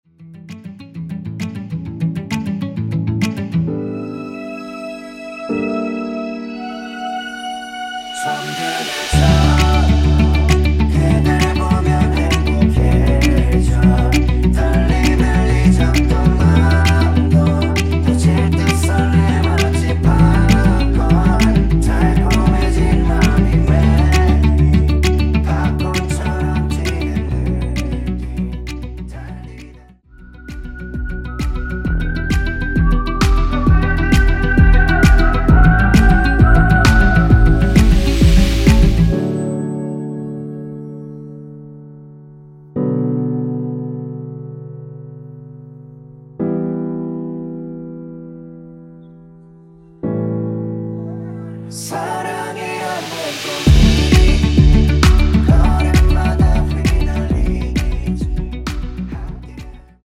원키 코러스 포함된 MR입니다.
앞부분30초, 뒷부분30초씩 편집해서 올려 드리고 있습니다.
중간에 음이 끈어지고 다시 나오는 이유는